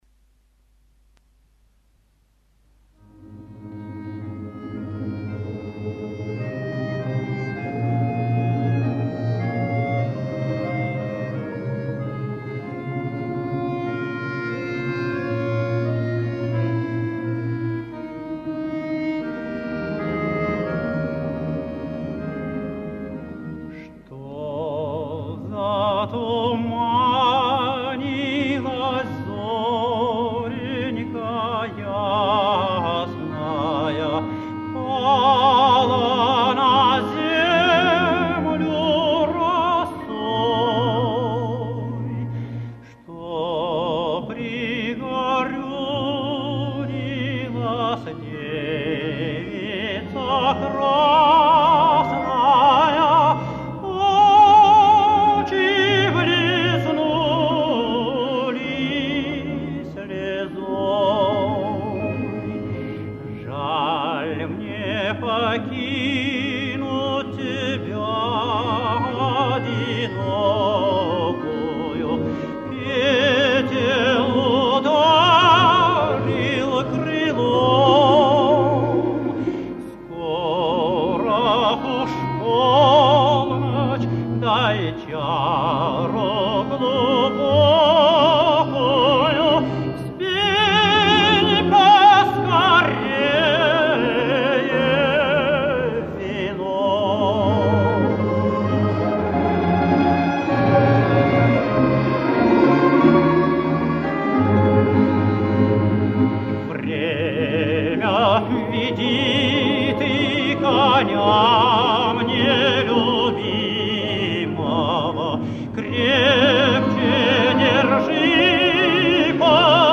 Известный шедевр русской разбойничьей песни.
Музыка: народная Слова